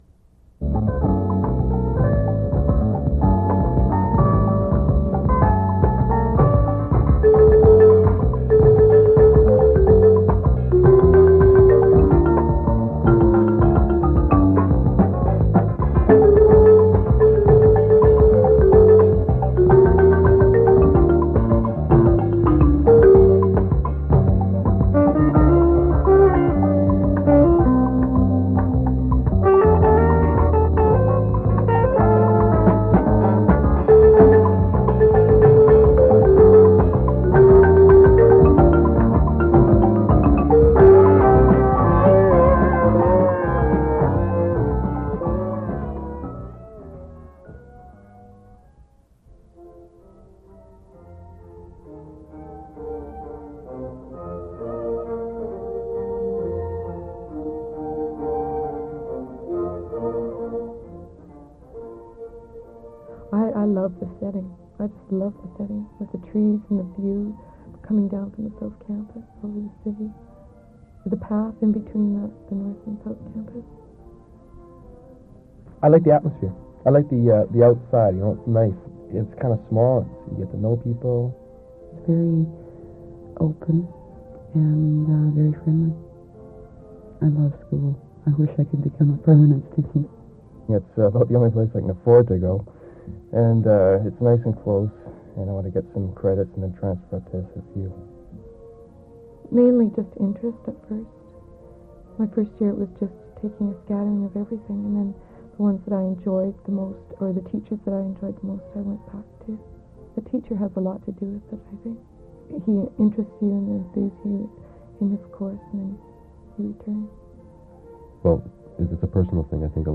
Audio track with voiceover narrative and background music created to accompany slide show for 10th anniversary of Capilano College.